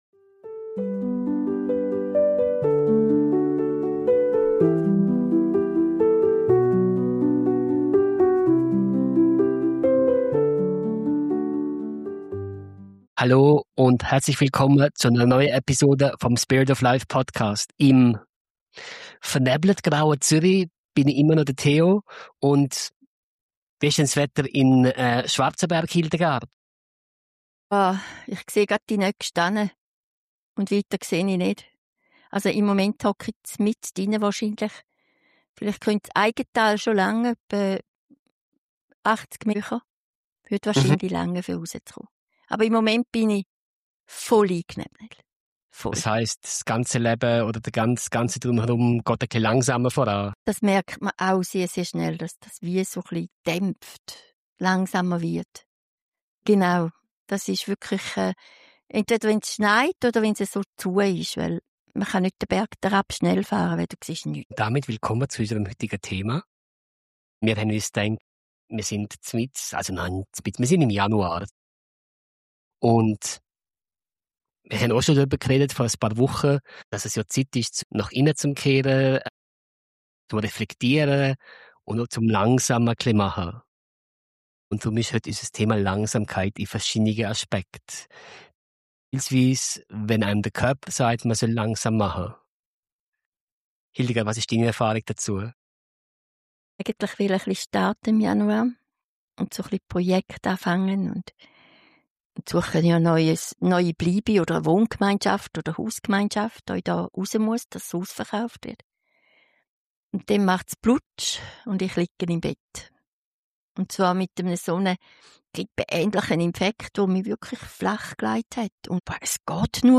Es geht um bewusstes Kochen, um das Wahrnehmen von Geschmack, um Kinder als natürliche Lehrer der Langsamkeit und um die Erkenntnis, dass Kreativität, Genuss und innere Ruhe nur entstehen, wenn wir uns Zeit geben. Eine warme, ruhige Episode über Rhythmus, Körperweisheit, Stille und darüber, warum jeder langsame Schritt ein sicherer Schritt ist.